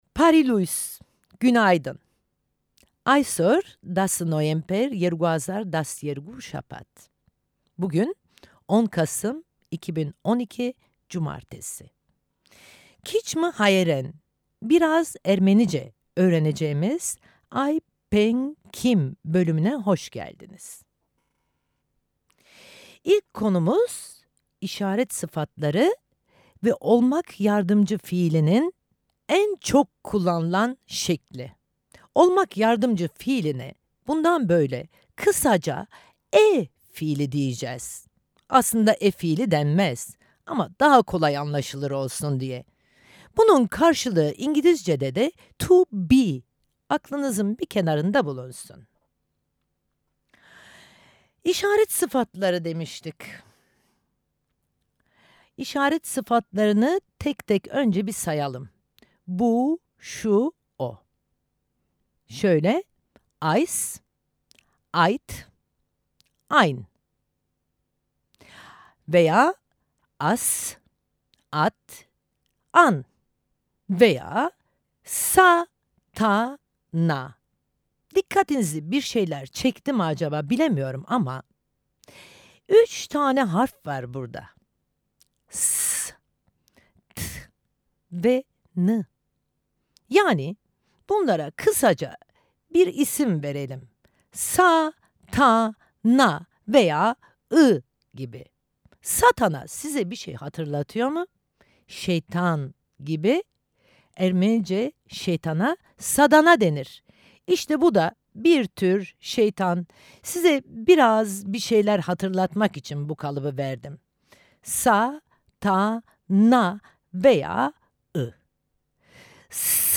Her Cumartesi saat 10’da, Açık Radyo 94.9’da.